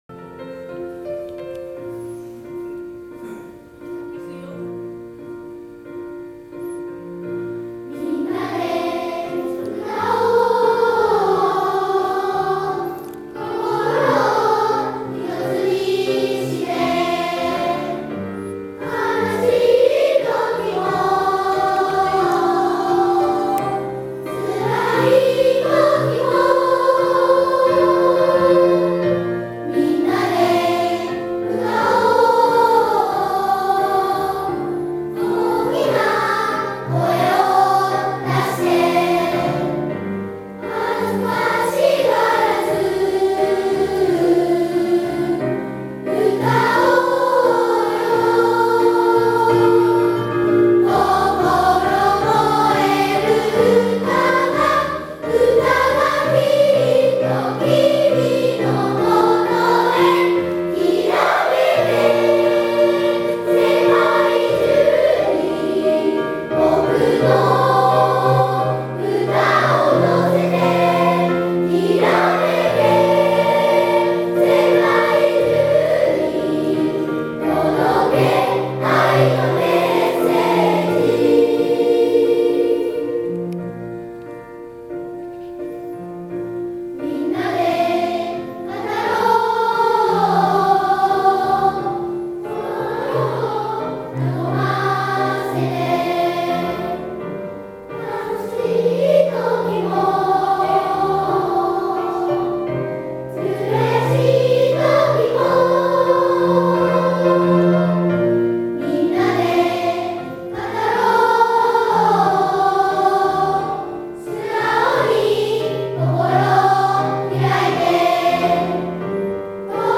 今日は音楽朝会がありました。文化祭の際の全校合唱（二部合唱）の低音・高音の重なりを中心に練習しました。１・２・５・６年生が高音、３・４年生が低音のパートです。喉を開き腹から声を出すこと、別パートの声を聞き取り合わせて歌うことなど課題はありますが、各学級での朝練習を重ねて行きま。